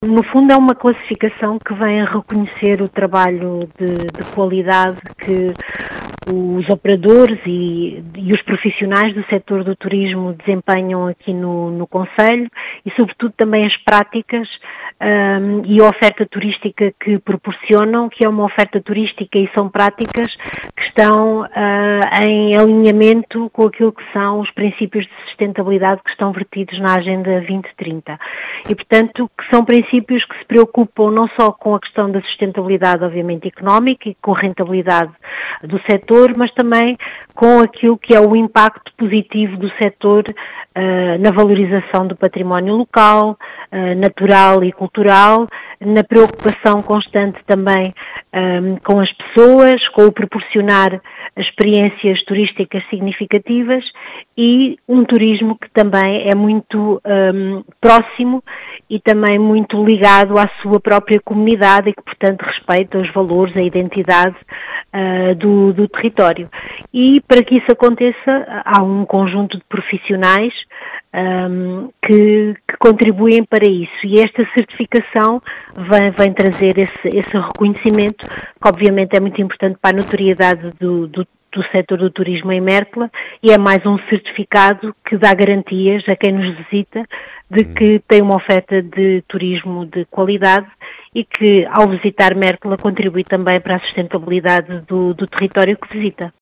As explicações fora deixadas pela vereadora Rosinda Pimenta, da Câmara de Mértola, que realça a importância desta certificação.